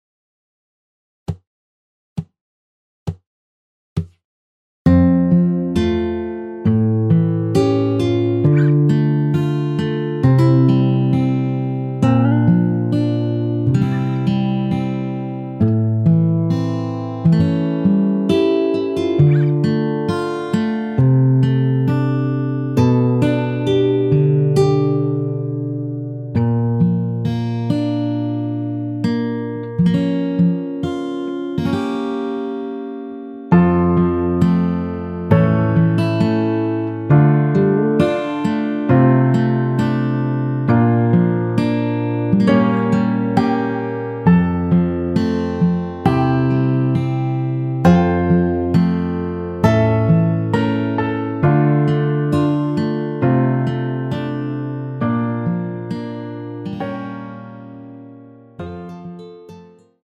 MR 입니다.
앞부분30초, 뒷부분30초씩 편집해서 올려 드리고 있습니다.
중간에 음이 끈어지고 다시 나오는 이유는